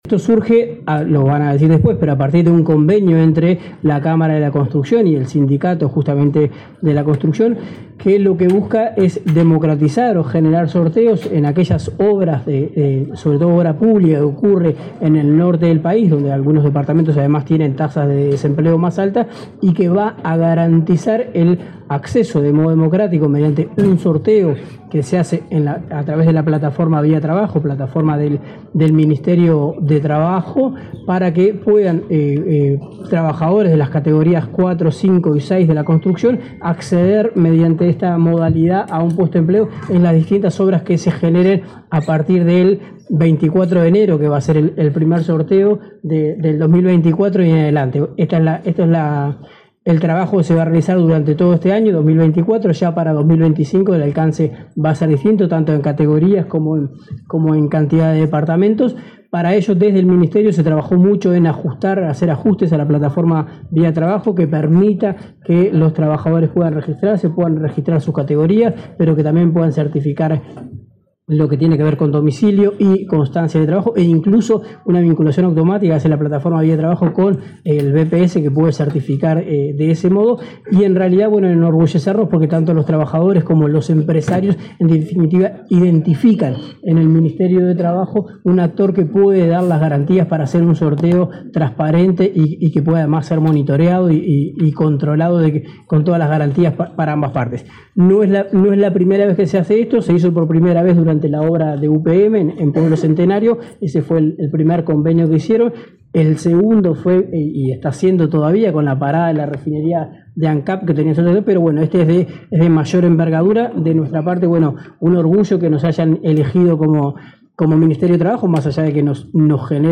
Palabras de autoridades del Ministerio de Trabajo